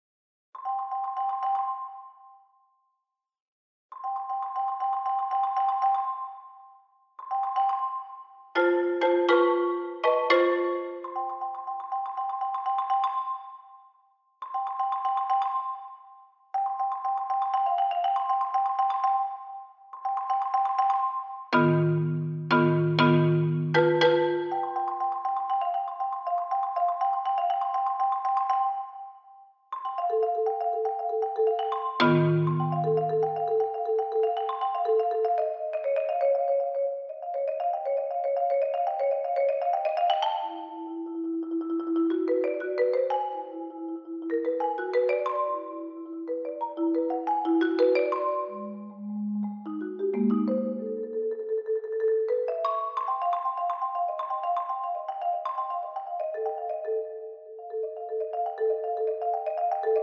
Saxophone, Percussion